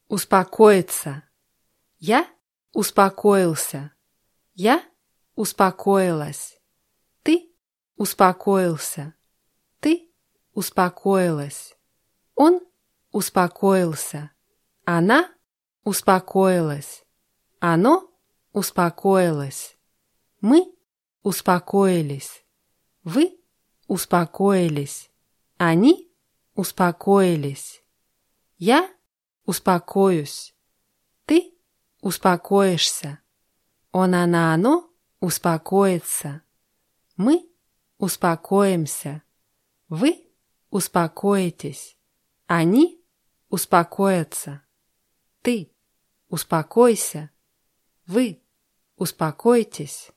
успокоиться [ußpakóitsa]